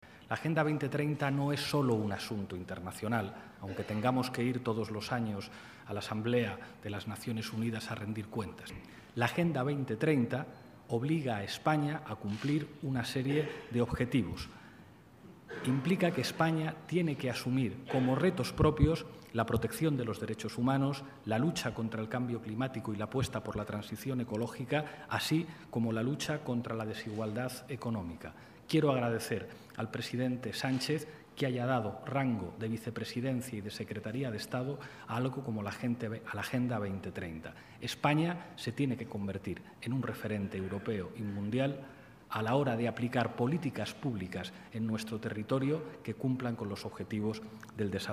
recordó Iglesias formato MP3 audio(0,73 MB) que, “aunque tengamos que ir todos los años a la Asamblea de la ONU a rendir cuentas, no se trata de un asunto internacional” sino con una clara exigencia de plasmación nacional, que -dijo- “espero permita convertir a España en referencia mundial en cuanto a cumplimiento de los objetivos de desarrollo”.